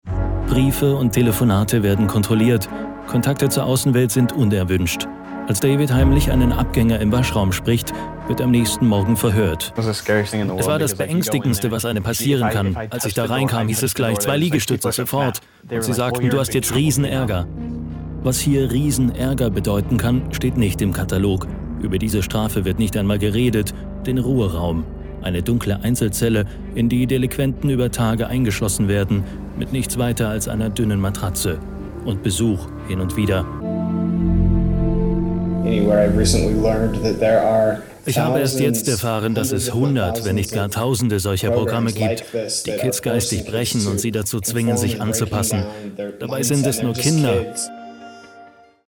Kein Dialekt
Sprechprobe: Sonstiges (Muttersprache):
stimmprobe_off-und-voiceover.mp3